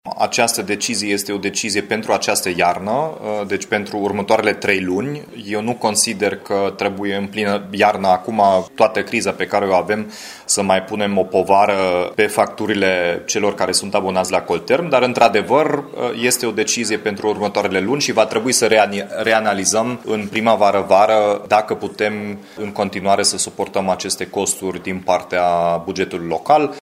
Primarul Dominic Fritz a declarat că suportarea majorării din bugetul local este doar pentru câteva luni, urmând ca, la primăvară sau la vară, tariful să fie rediscutat.